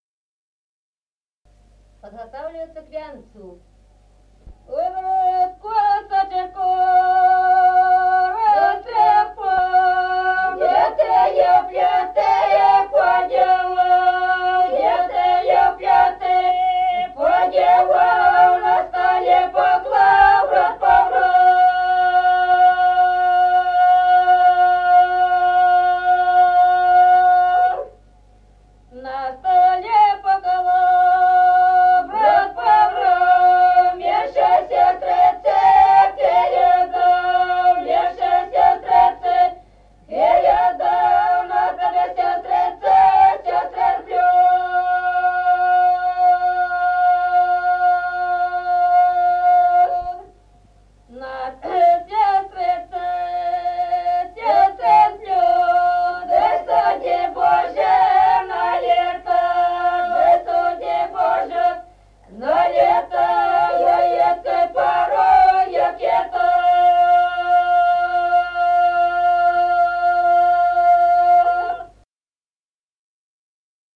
Народные песни Стародубского района «А брат косочку растрепал», свадебная, «подготавливаются к венцу».
(запев)
(подголосник)
с. Остроглядово.